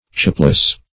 Chapeless \Chape"less\, a.